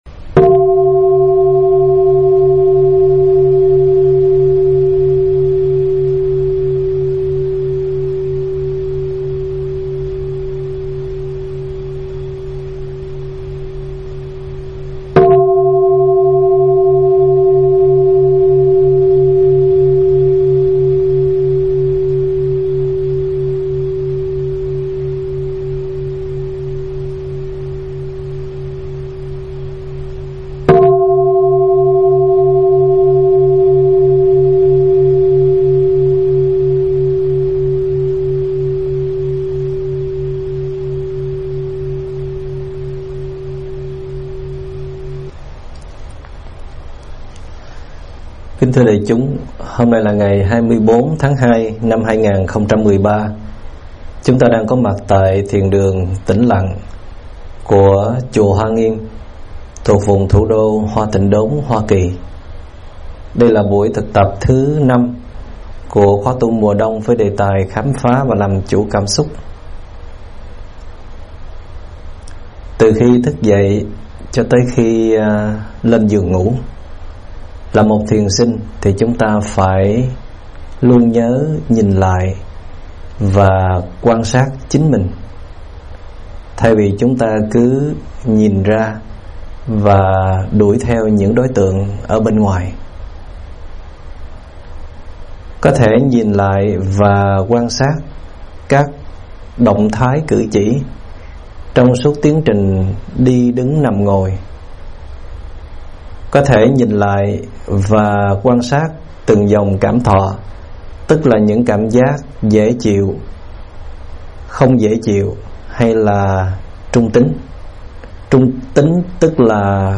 Nghe Mp3 thuyết pháp Cảnh Giác Với Những Cám Dỗ Bên Trong - ĐĐ. Thích Minh Niệm
Mời quý phật tử nghe mp3 thuyết pháp Cảnh Giác Với Những Cám Dỗ Bên Trong do ĐĐ. Thích Minh Niệm giảng tại chùa Hoa Nghiêm ngày 24 tháng 2 năm 2013